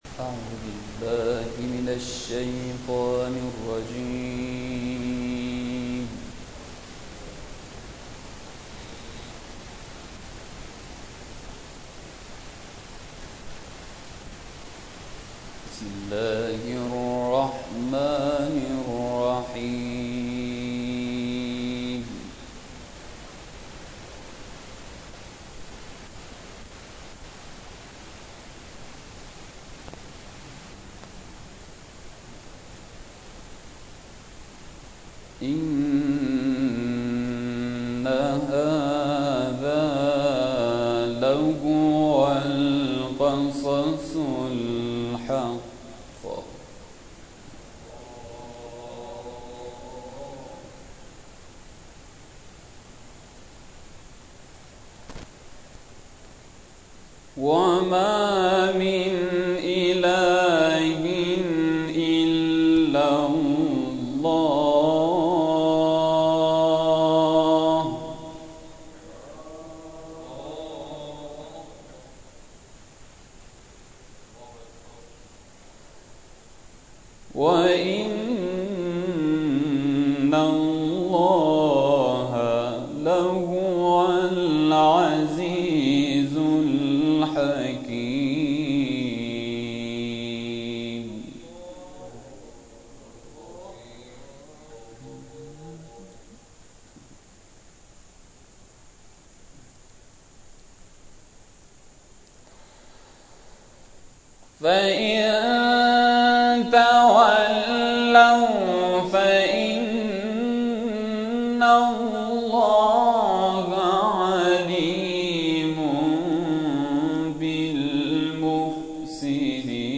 شب گذشته در جلسه قرآن مطرح شد؛
در ادامه تلاوت‌های این جلسه ارائه می‌شود.